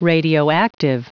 Prononciation du mot radioactive en anglais (fichier audio)
Prononciation du mot : radioactive